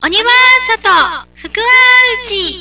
声優